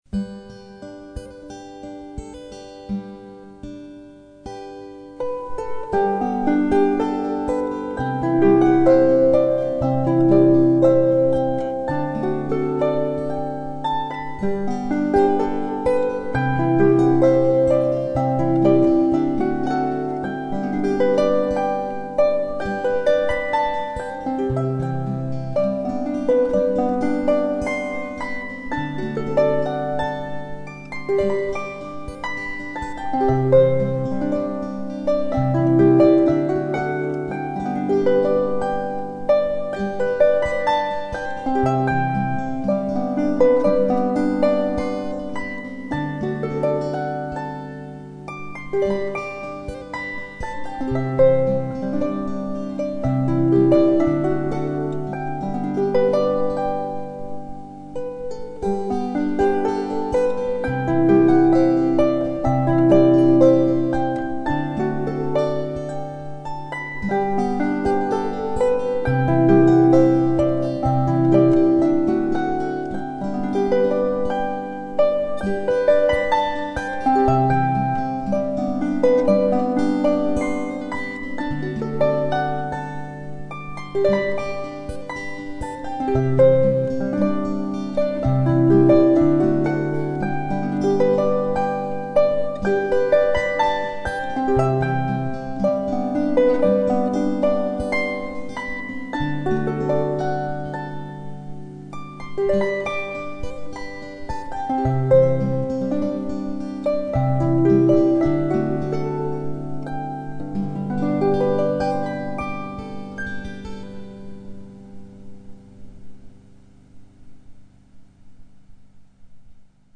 Celtic Twist - traditional Scottish Harp and Guitar duo.
String Musicians